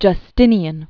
(jŭ-stĭnē-ən) Originally Flavius Petrus Sabbatius Justinianus.